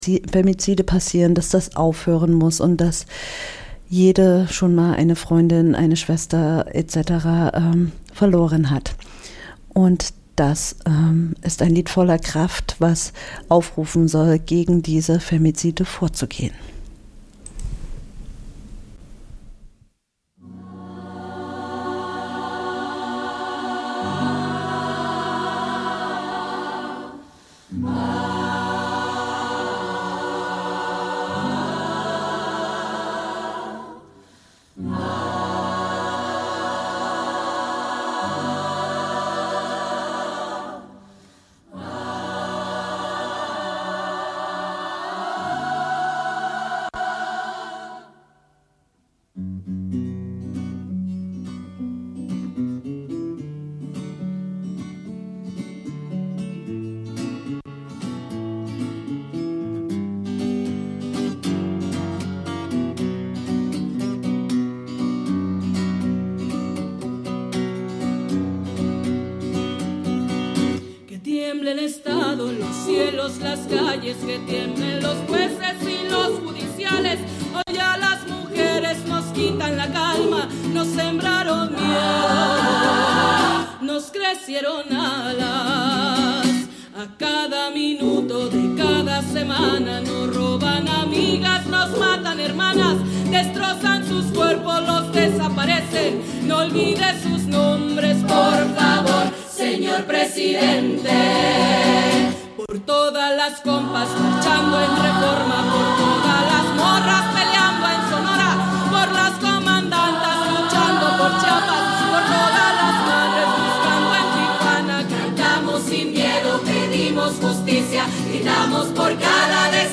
Bei den verschiedenen Veranstaltungen im Rahmen der Kraftwerkewoche habe ich Stimmen zum Thema Feminismus eingefangen.
Die Sendung ist wie immer voller Musik aus Lateinamerika, diesmal ausschlie�lich feministische K�nstlerinnen, die in ihren Liedern viele Themen der Sendung beschreiben.